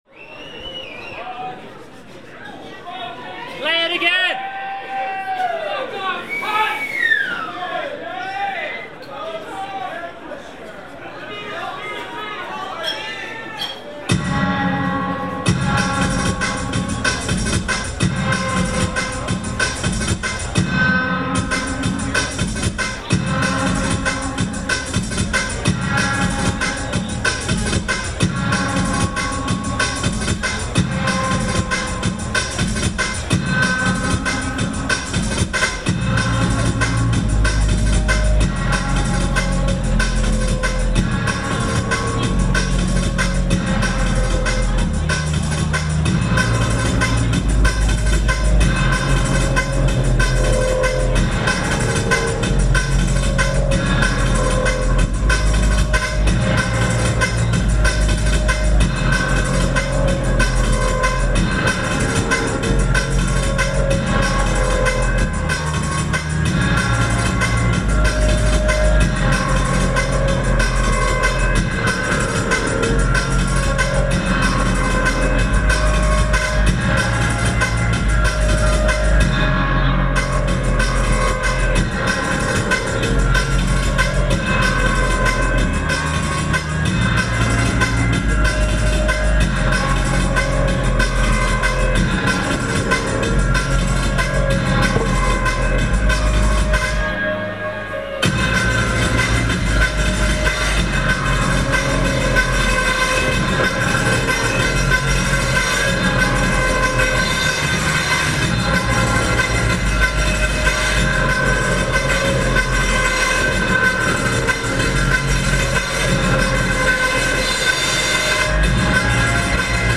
location San Francisco, USA